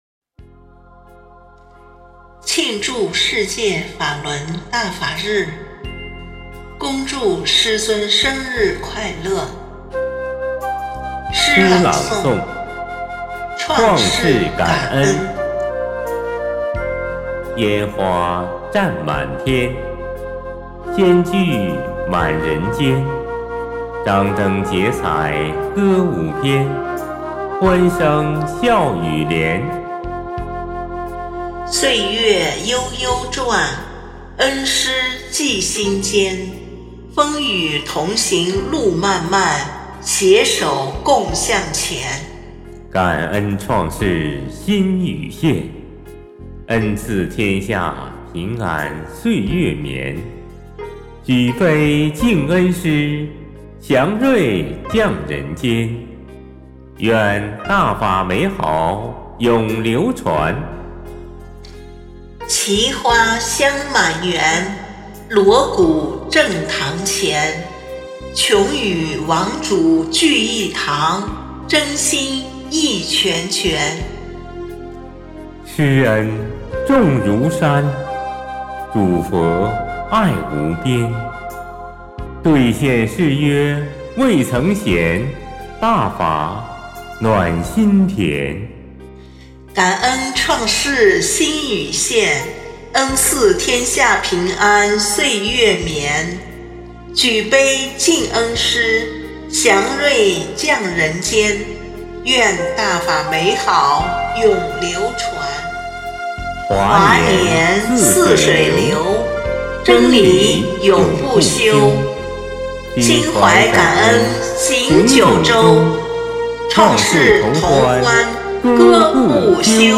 【慶祝世界法輪大法日】配樂詩朗誦（音頻）：創世感恩 | 法輪大法正見網